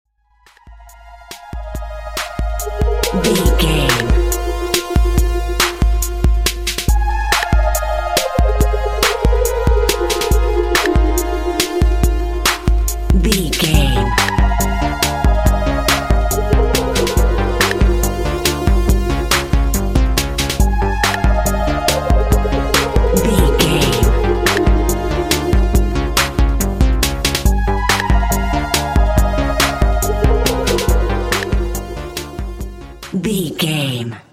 Epic / Action
Fast paced
Aeolian/Minor
dark
high tech
futuristic
driving
energetic
tension
synthesiser
drum machine
percussion
breakbeat
power rock
synth lead
synth bass
synth drums